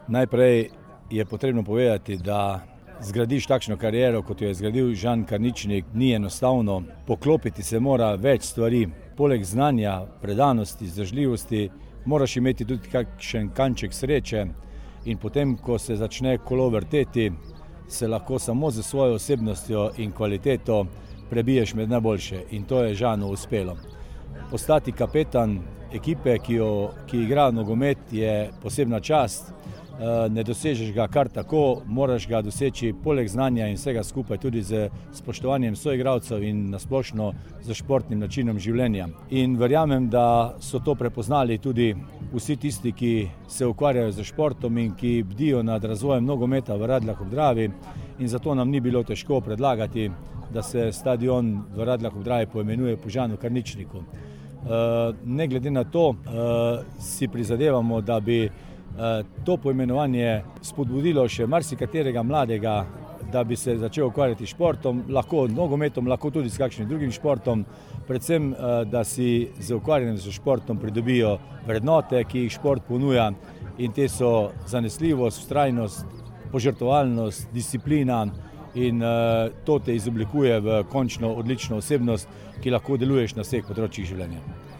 Radeljski župan Alan Bukovnik je izpostavil, da je Žan Karničnik, uspešen nogometaš, velik zgled mladim v več pogledih:
izjava Bukovnik - Stadion  (4).mp3